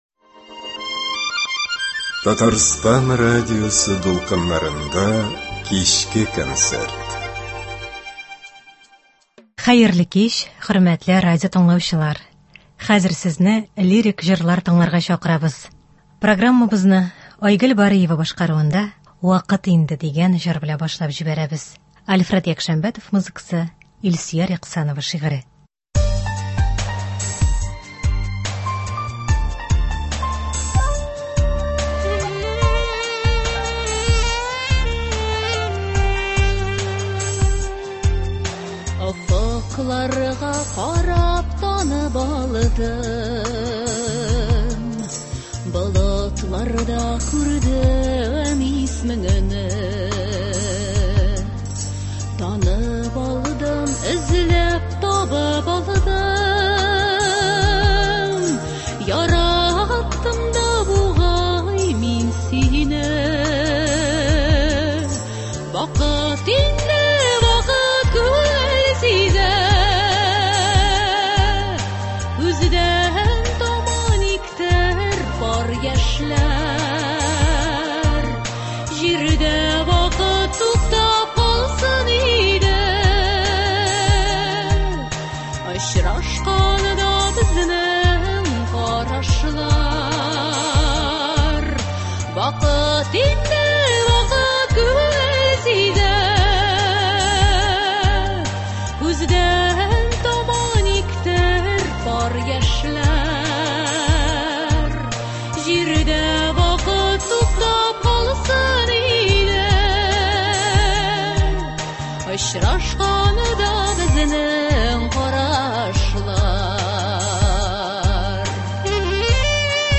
Лирик концерт (10.11.23)